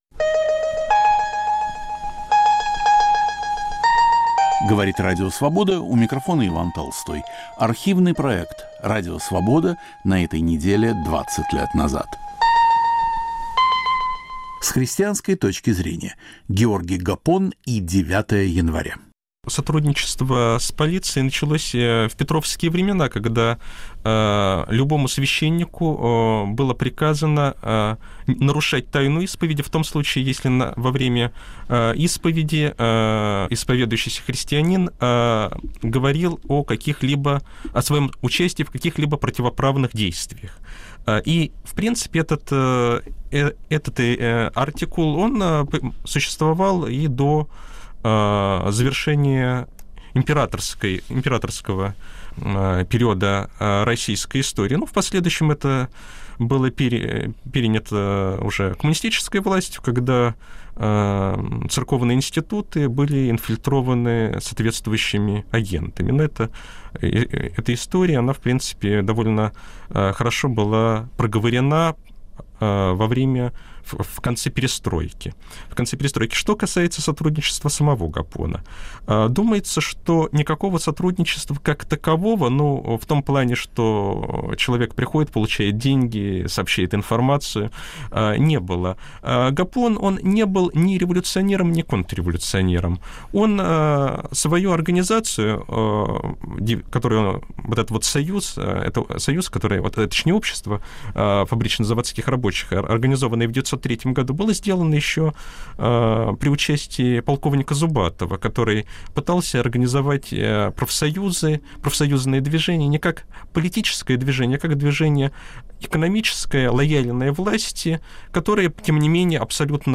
В студии историк